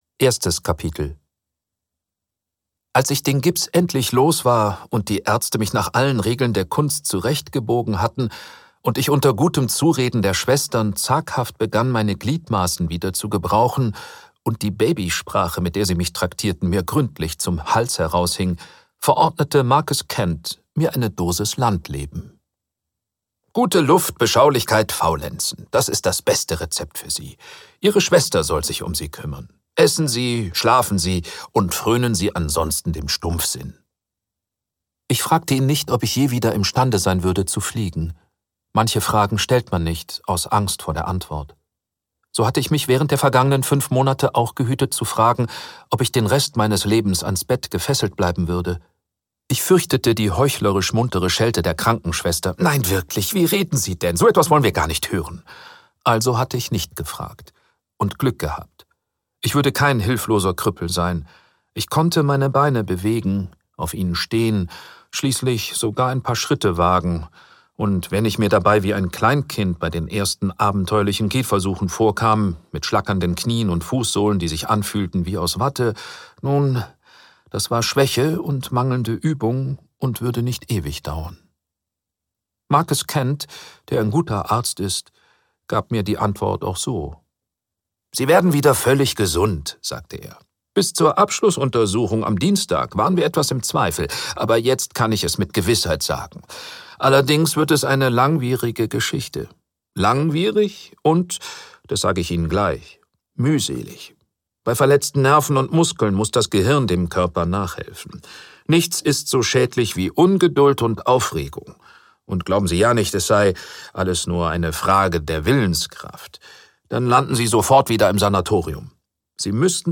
Die Schattenhand (DE) audiokniha
Ukázka z knihy
• InterpretThomas Loibl